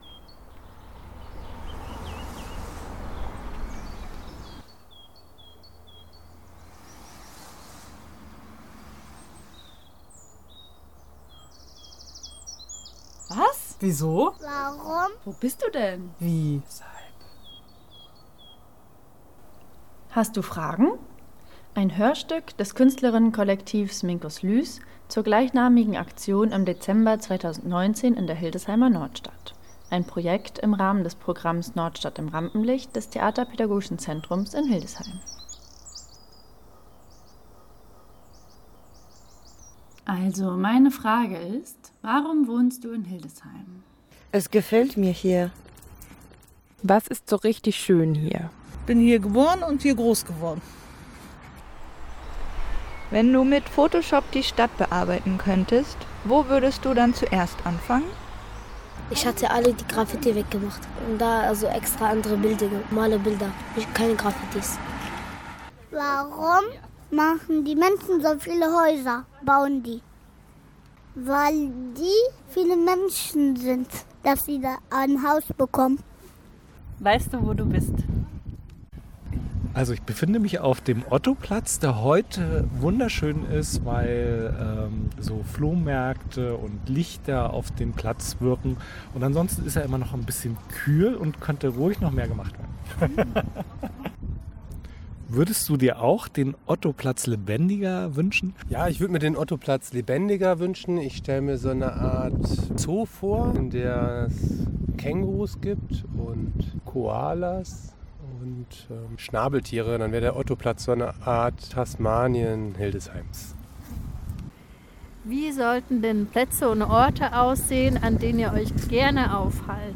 Jetzt ist aus dieser künstlerischen Aktion ein Hörstück entstanden, das einen Einblick in die Erlebnisse aus dem letzten Jahr bietet.